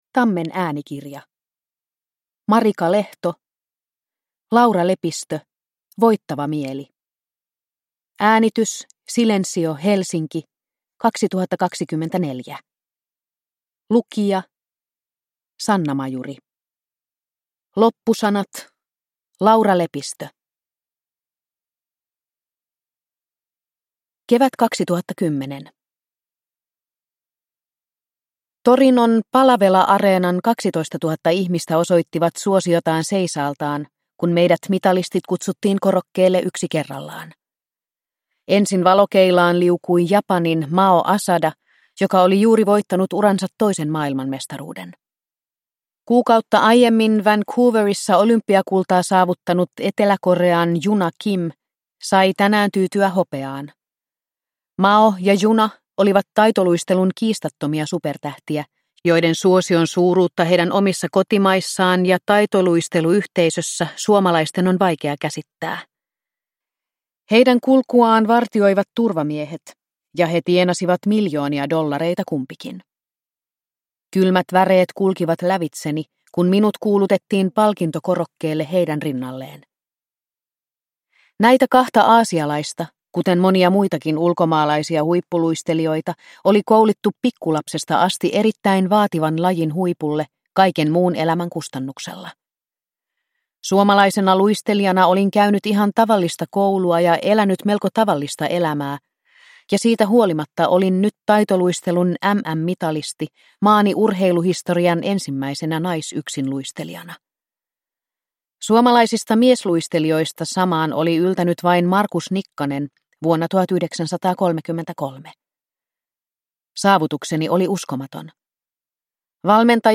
Laura Lepistö - Voittava mieli – Ljudbok